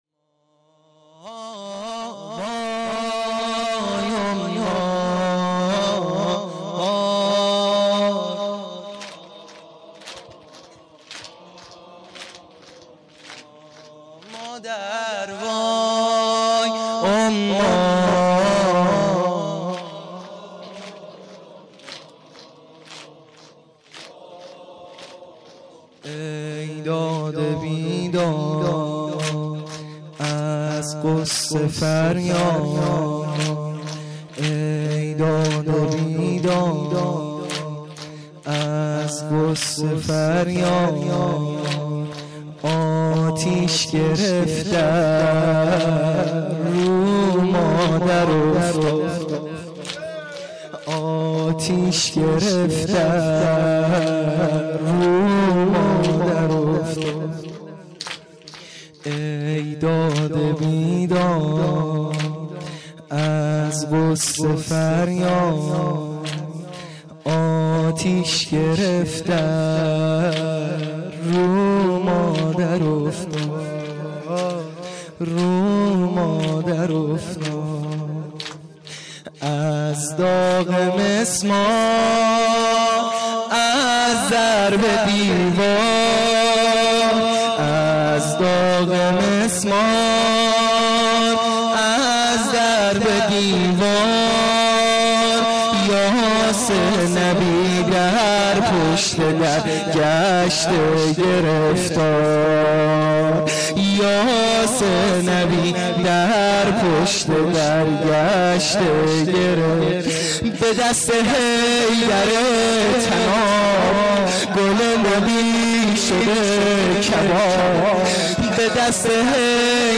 زمینه | ای داد و بیداد
فاطمیه اول 1396